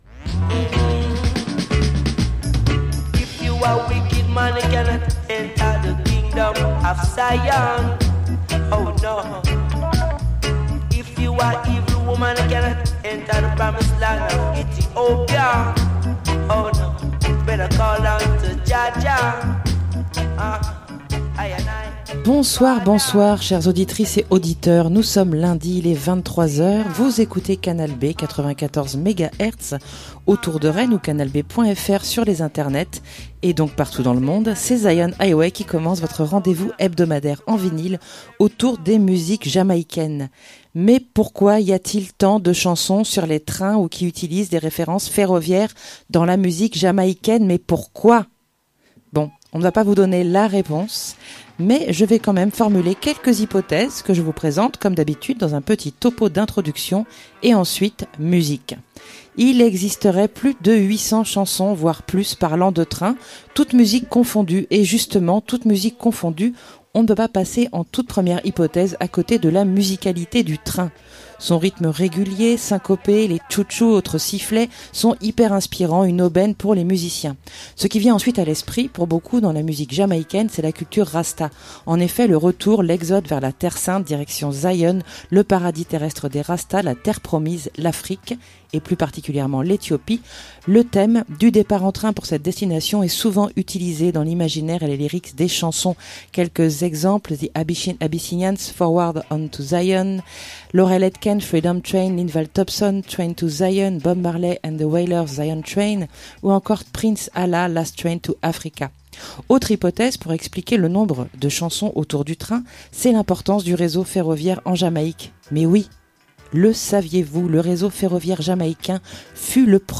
Alors je ne vais pas vous donner LA réponse mais je vais quand même formuler quelques hypothèses que je vous présente, comme d’habitude dans un petit topo d’introduction et ensuite, musique!